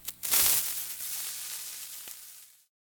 fuse.ogg